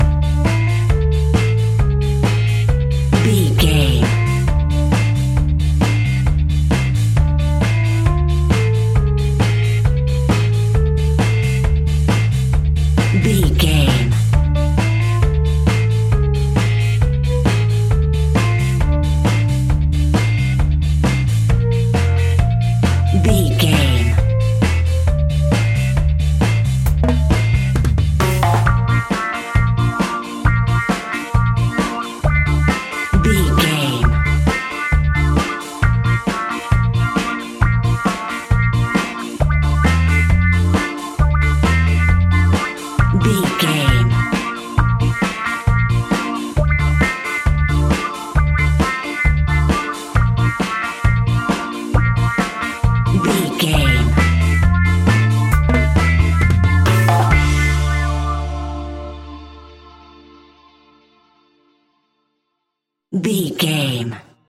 Classic reggae music with that skank bounce reggae feeling.
Aeolian/Minor
dub
instrumentals
laid back
chilled
off beat
drums
skank guitar
hammond organ
percussion
horns